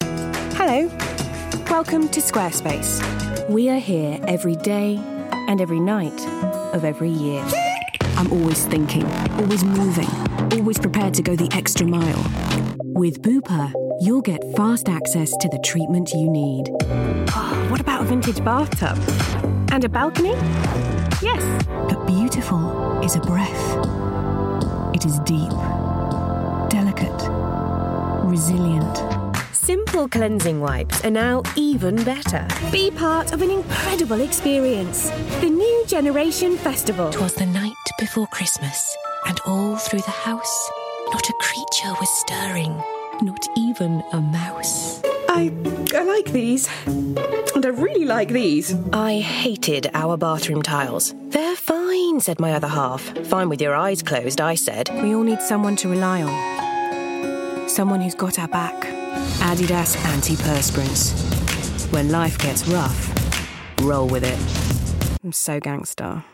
Inglés (Reino Unido)
Authoritative Trustworthy Warm